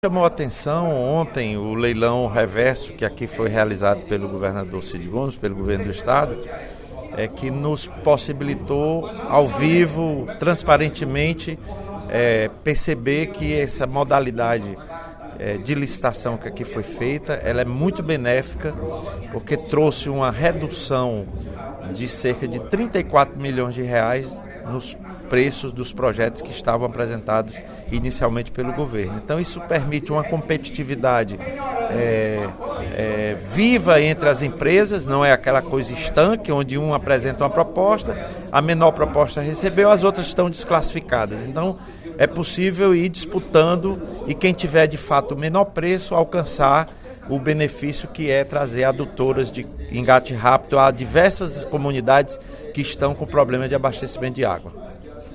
No primeiro expediente da sessão plenária desta sexta-feira (30/05), o deputado Lula Morais (PcdoB) elogiou a ação do Governo do Estado, que realizou, na quinta-feira (29/05), no Plenário 13 de Maio, o leilão reverso de contratação de empresas para a execução de adutoras emergenciais.